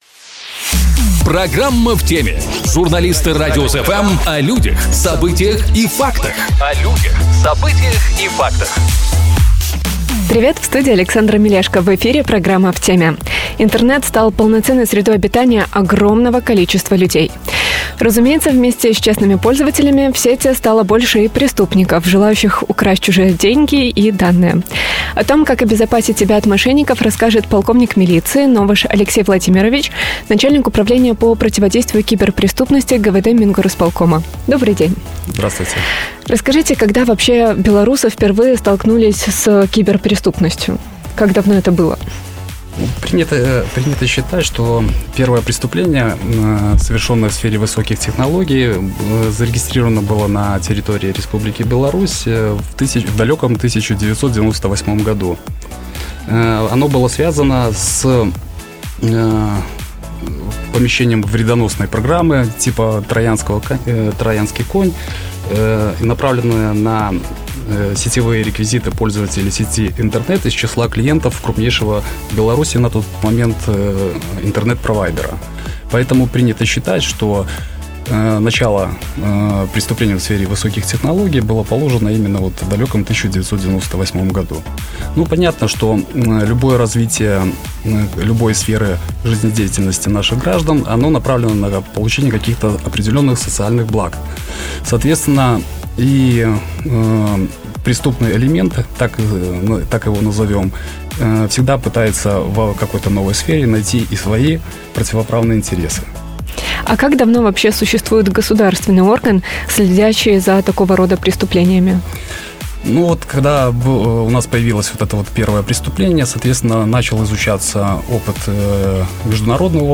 О том, как обезопасить себя от мошенников, расскажет полковник милиции